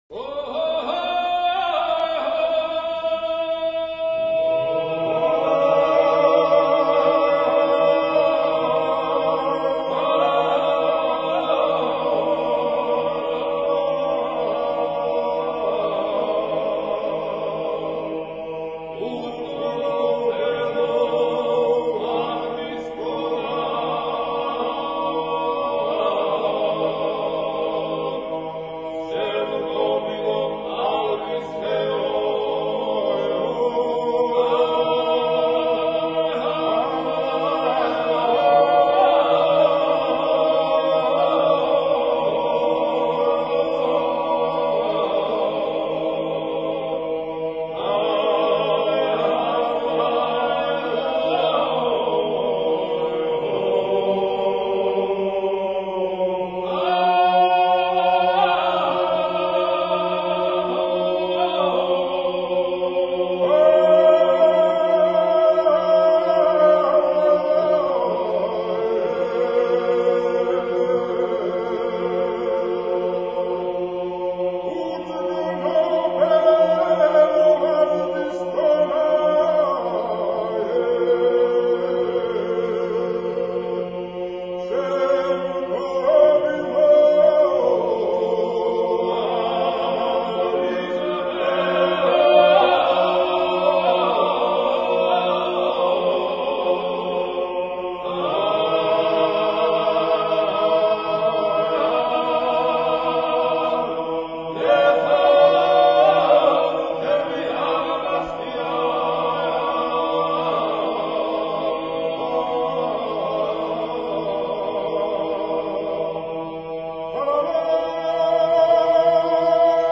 A drinking song.